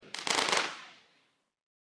Firework-sparkle-sound-effect.mp3